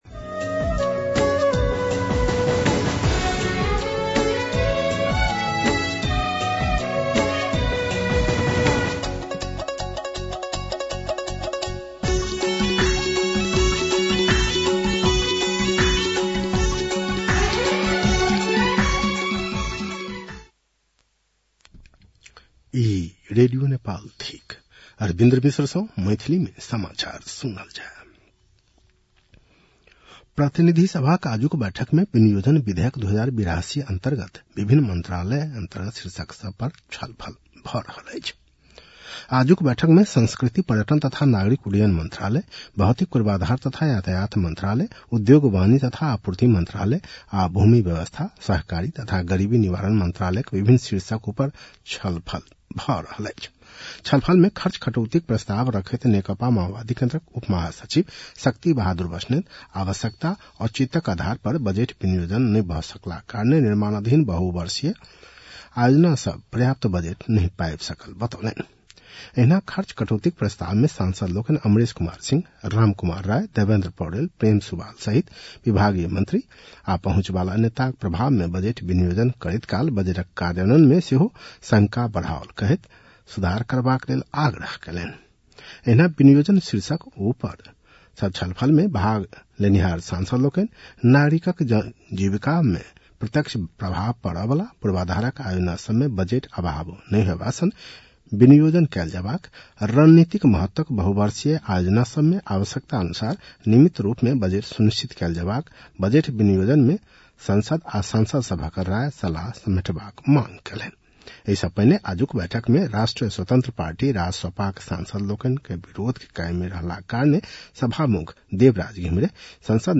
मैथिली भाषामा समाचार : ६ असार , २०८२